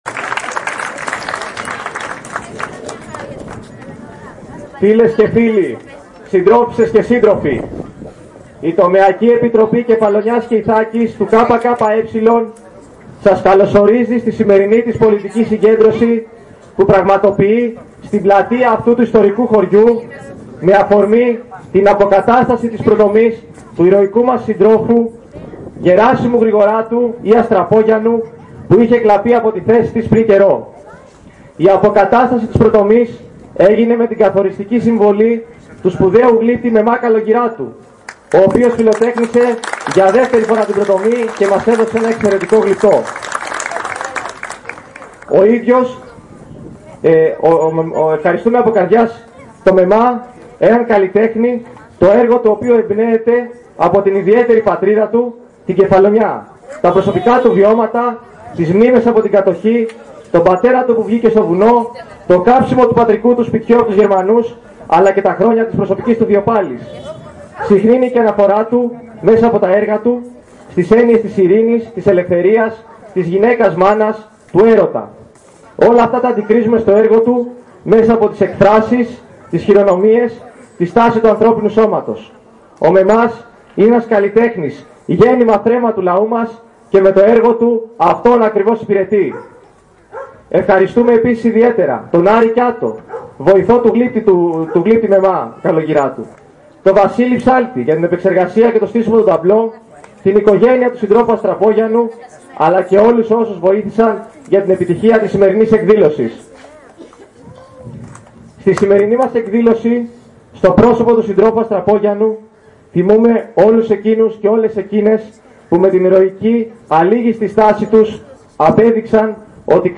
Η ΤΕ Κεφαλονιάς και Ιθάκης του ΚΚΕ τίμησε την μνήμη των αγωνιστών του Δημοκρατικού Στρατού Ελλάδας στη πλατεία στα Ζερβάτα.